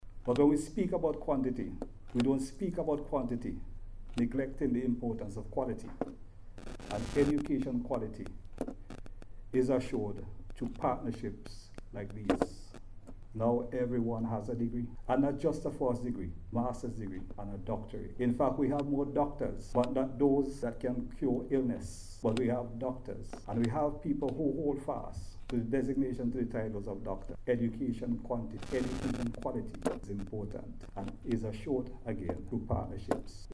Speaking at the official launch and signing of a partnership agreement between DeVry University and the Chamber of Industry and Commerce, Burke hailed the collaboration as a step towards strengthening workforce competence.